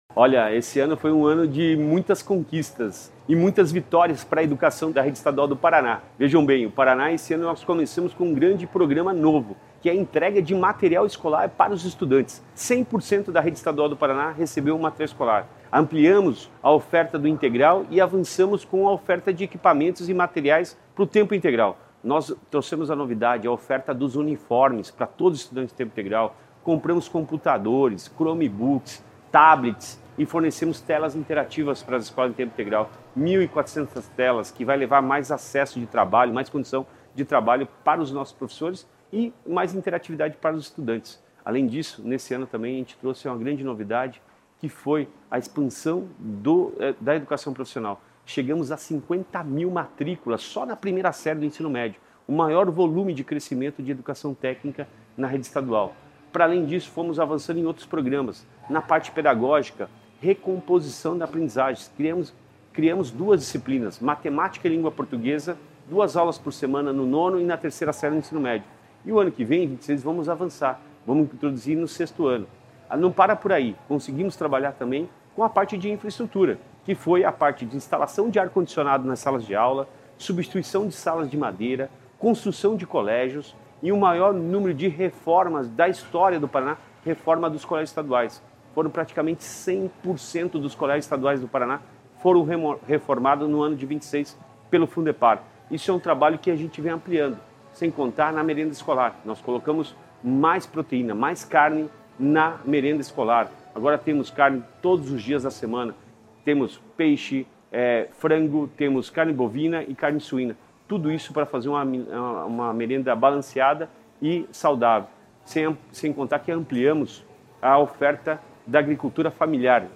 Sonora do secretário da Educação, Roni Miranda, sobre o Segundo Balanço Semestral da Educação